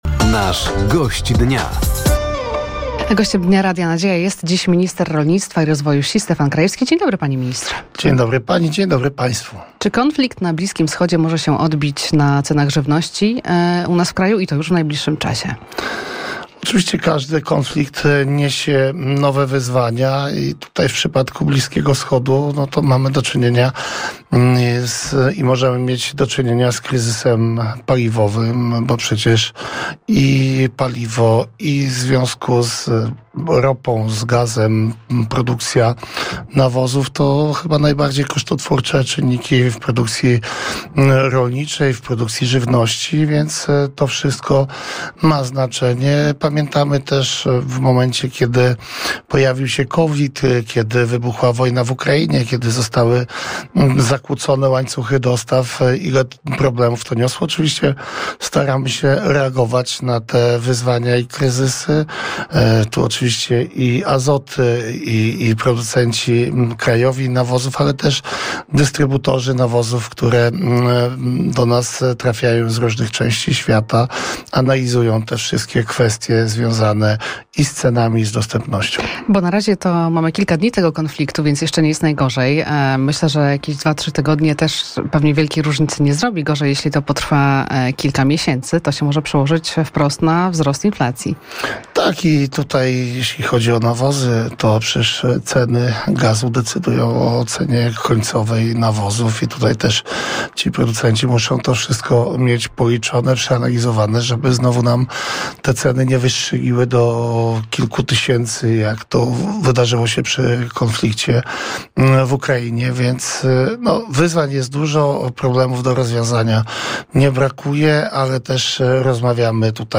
Studio Radia Nadzieja odwiedził Stefan Krajewski, minister Rolnictwa i Rozwoju Wsi.